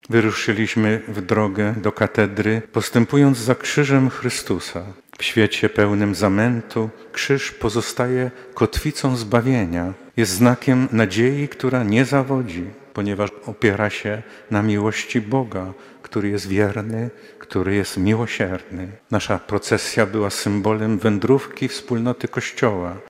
Archidiecezja lubelska oficjalnie zainaugurowała Rok Jubileuszowy 2025 ustanowiony przez papieża Franciszka. Uroczystość odbyła się w Archikatedrze Lubelskiej w niedzielę (29.12) Świętej Rodziny.
Mszy przewodniczył Metropolita Lubelski arcybiskup Stanisław Budzik.
Do tego wydarzenia arcybiskup Budzik nawiązał podczas homilii.